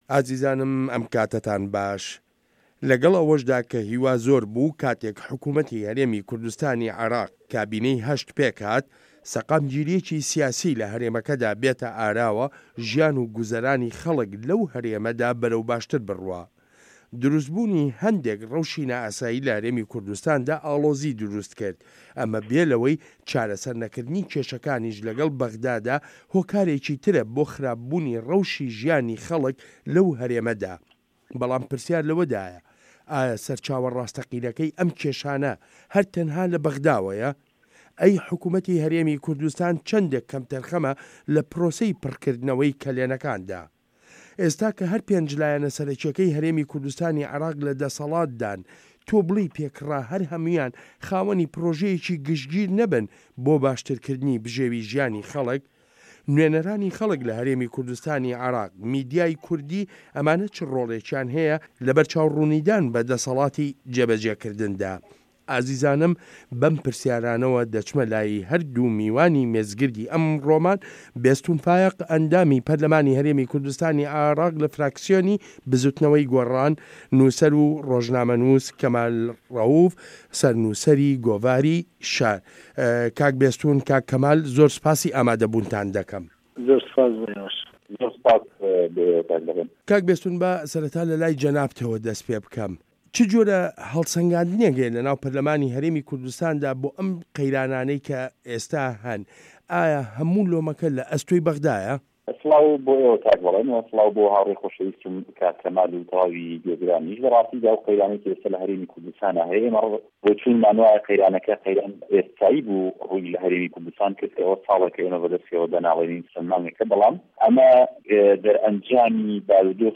مێزگرد: قه‌یرانی دارایی هه‌رێمی کوردستان